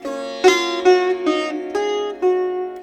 SITAR LINE54.wav